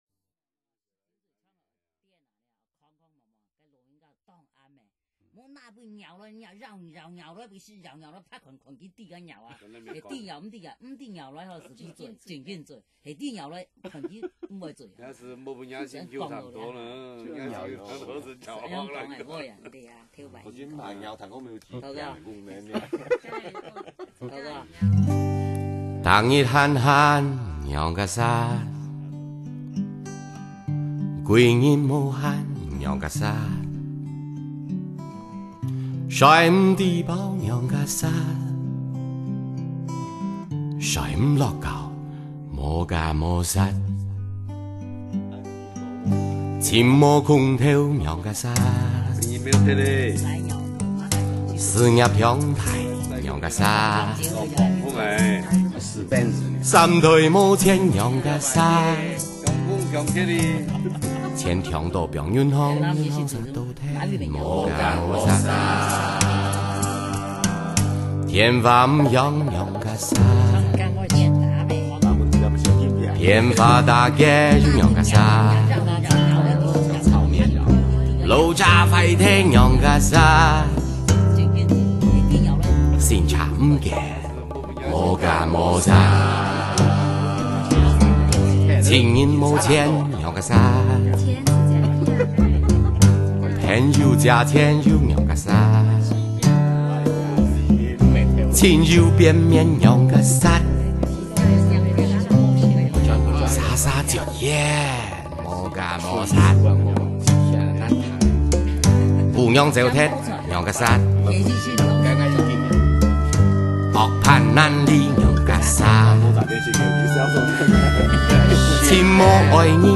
【客家專輯】